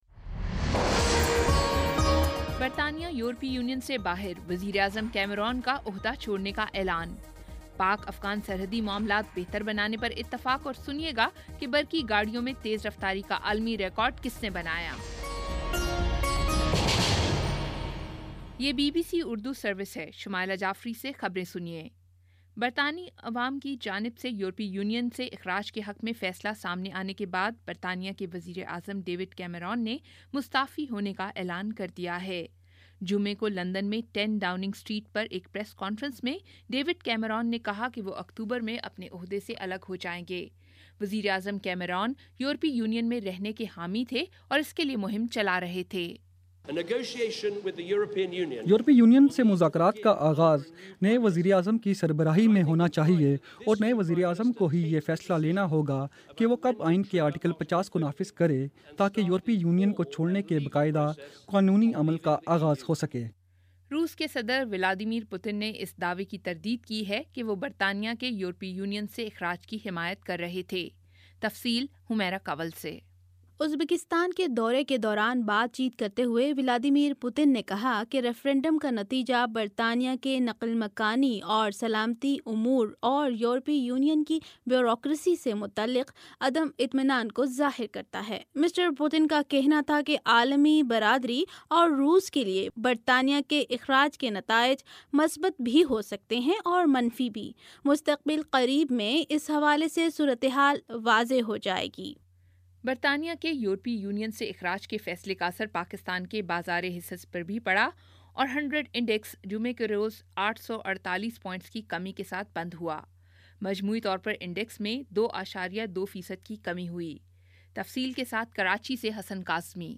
جون 24 : شام سات بجے کا نیوز بُلیٹن